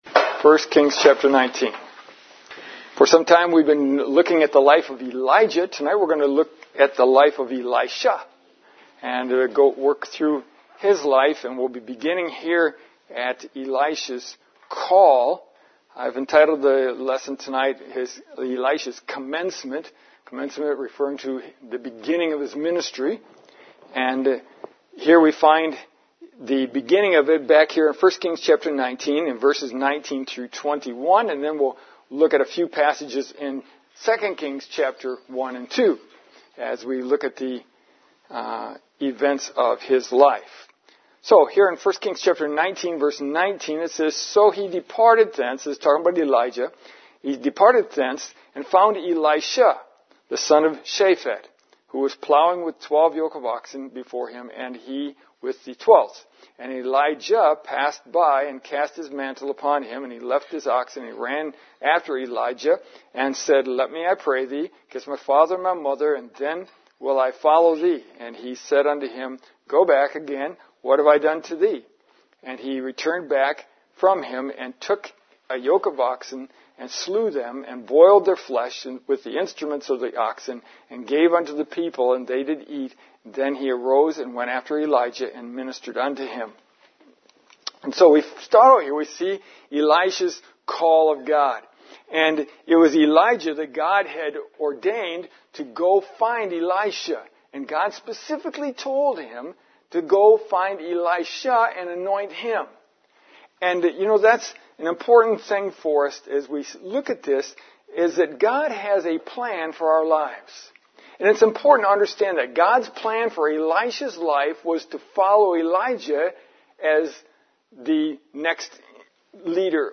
Elisha The Prophet of God Lesson 1 – Commencement 1Kings 19:19-21; 2Kings 1-2 Date: Thursday, 18 June 2020 –Elisha20 1.